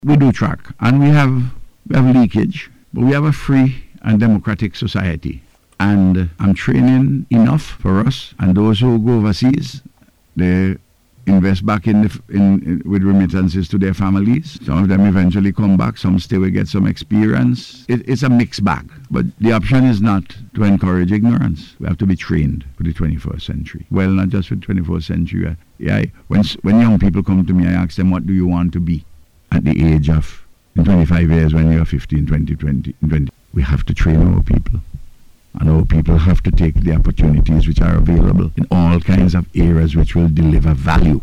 Speaking on NBC Radio’s Face to Face programme on Wednesday, Dr. Gonsalves acknowledged there is some “leakage” when scholarship recipients remain abroad, but noted many still contribute by supporting their families in St. Vincent and the Grenadines.